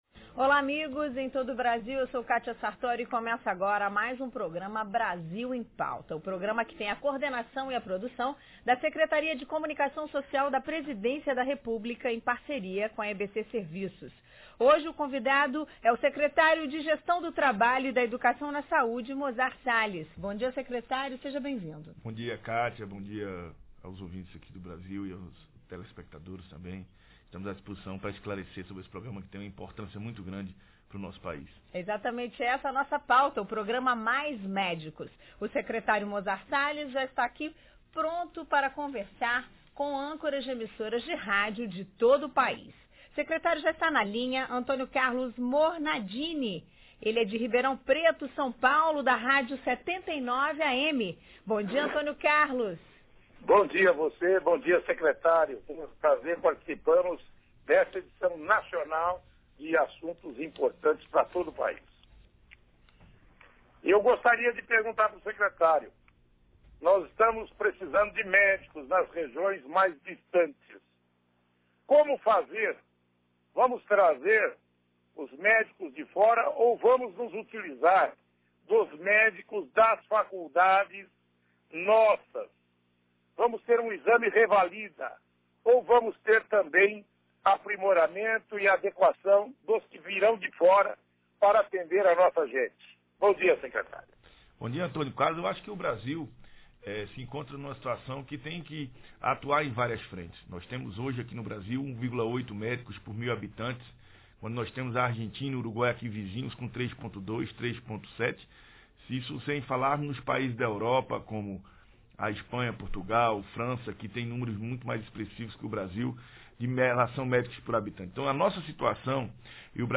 audio da entrevista do secretario de gestao do trabalho e da educacao na saude mozart sales ao brasil em pauta 45min00s